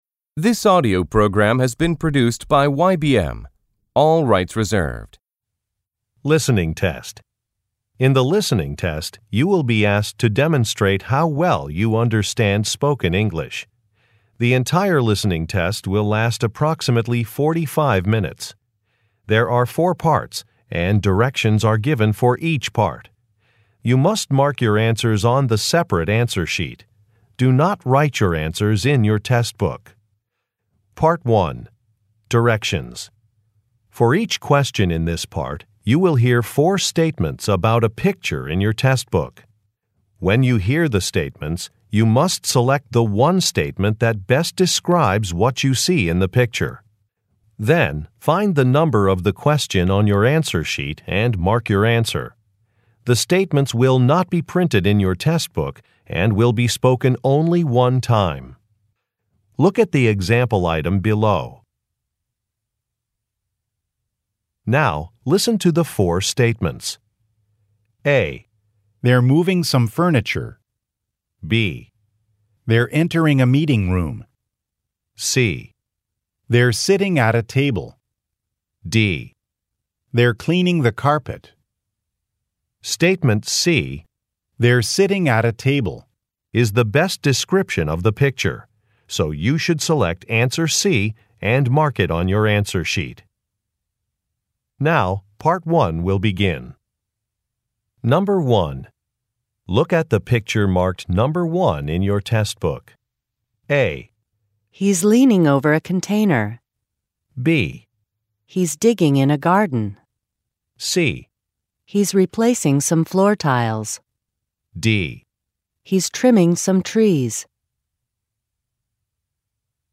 In the listening test, you will be asked to demonstrate how well you understand spoken English.